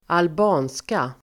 Uttal: [alb'a:nska]